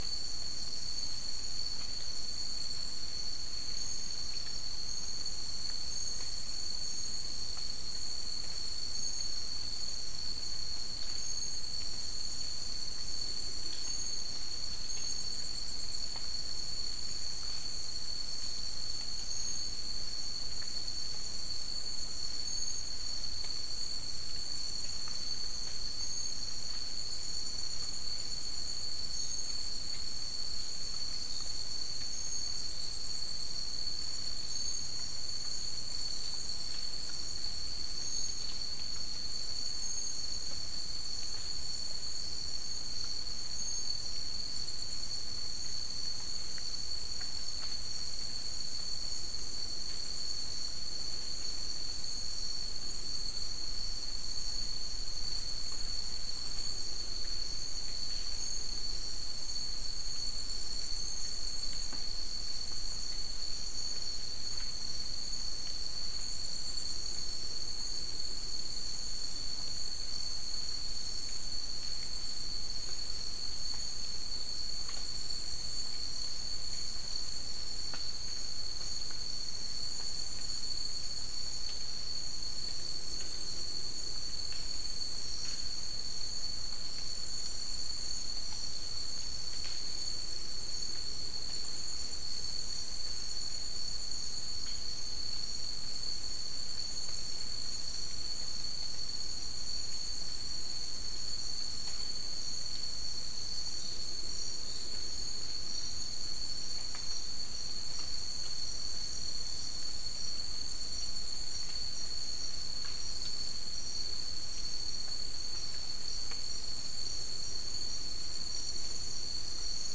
Non-specimen recording: Soundscape Recording Location: South America: Guyana: Rock Landing: 4
Recorder: SM3